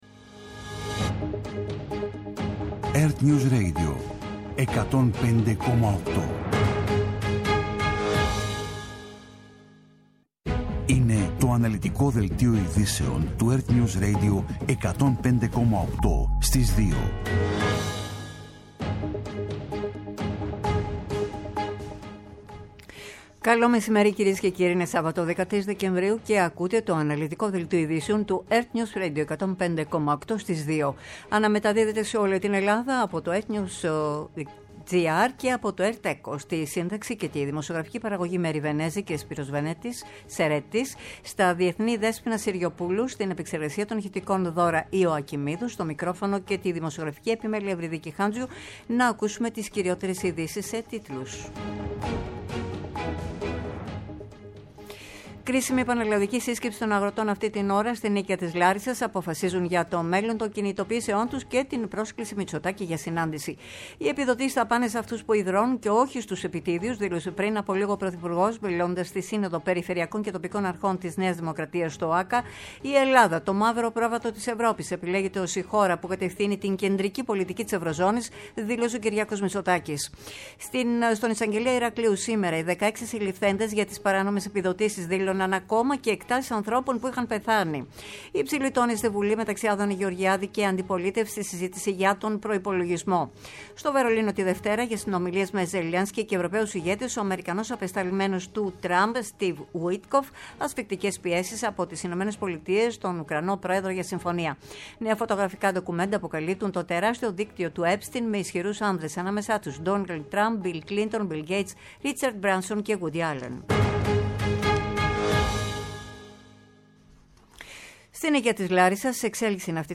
Το κεντρικό ενημερωτικό μαγκαζίνο στις 14.00.
Με το μεγαλύτερο δίκτυο ανταποκριτών σε όλη τη χώρα, αναλυτικά ρεπορτάζ και συνεντεύξεις επικαιρότητας.